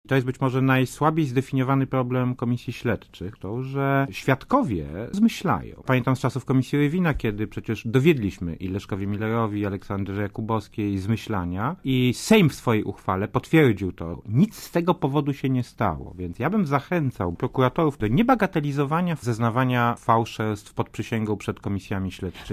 Jan Rokita w Radiu Zet (PAP)
Tak uważa Jan Rokita z Platformy Obywatelskiej, piątkowy gość Radia ZET.
Mówi Jan Rokita